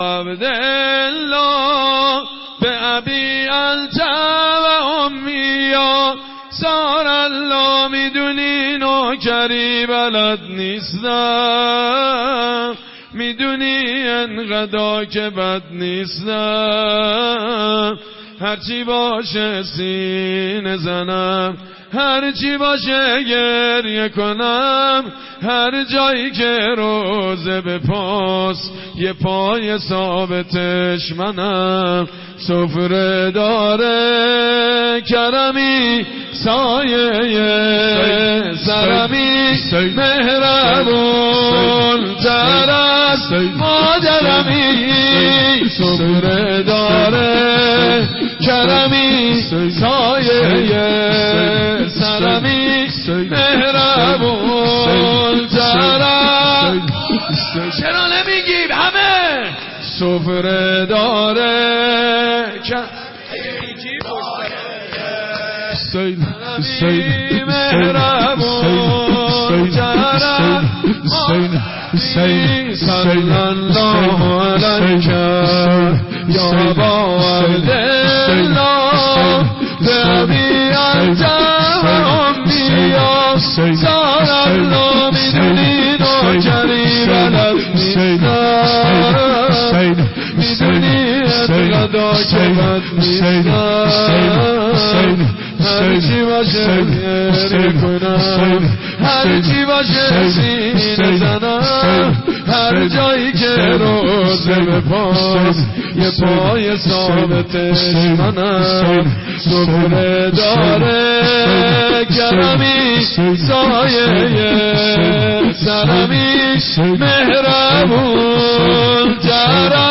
عقیق : مراسم عزاداری دهه دوم محرم با حضور جمع زیادی از عاشقان اهل بیت (ع) در هیئت آل یاسین برگزار شد.